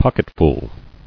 [pock·et·ful]